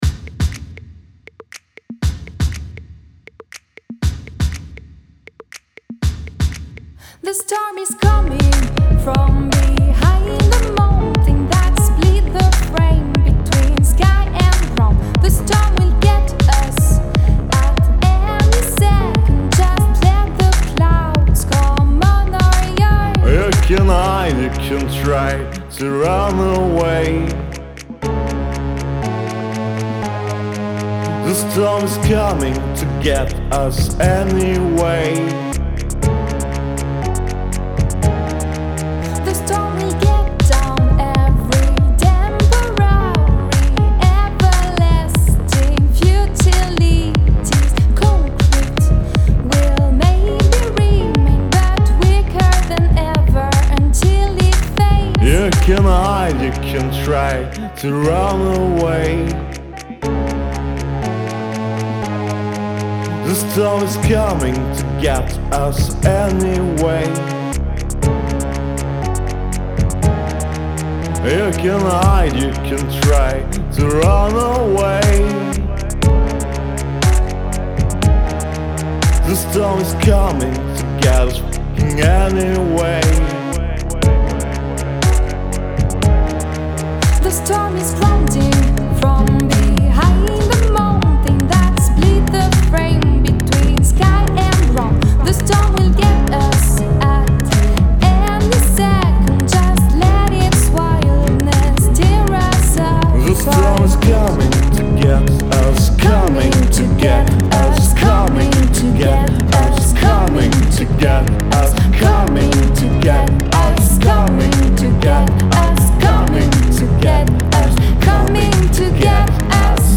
storm.mp3